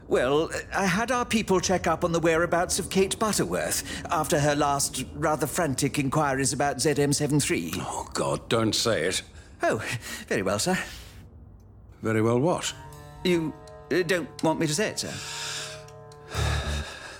Audio Drama
Memorable Dialog